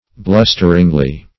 blusteringly - definition of blusteringly - synonyms, pronunciation, spelling from Free Dictionary Search Result for " blusteringly" : The Collaborative International Dictionary of English v.0.48: Blusteringly \Blus"ter*ing*ly\, adv. In a blustering manner.